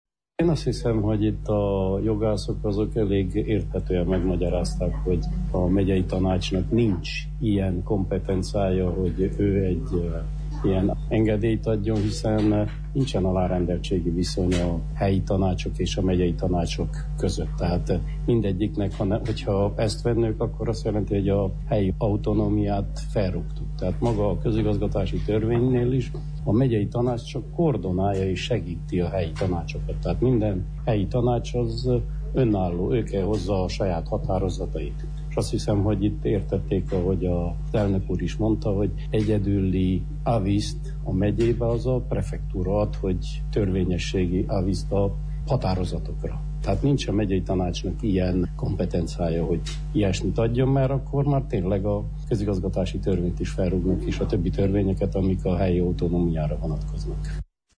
Szabó Árpádot a megyei tanács alelnökét hallják.